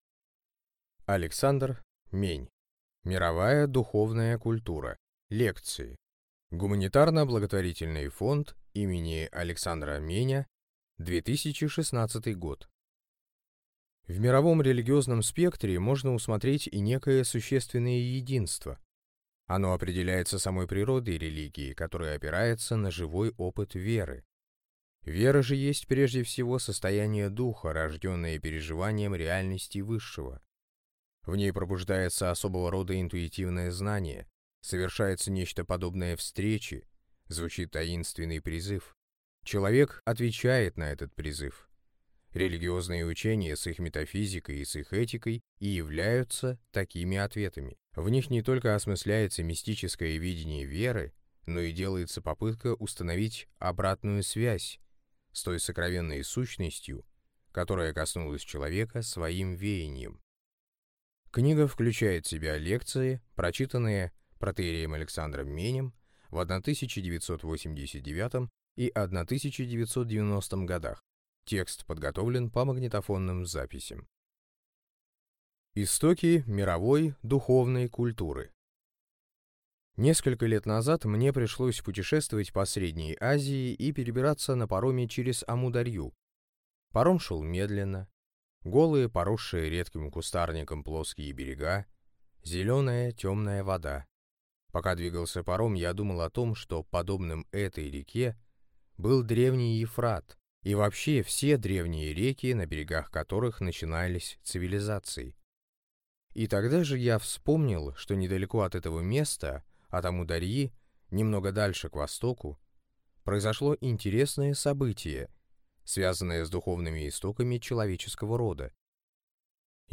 Аудиокнига Мировая духовная культура | Библиотека аудиокниг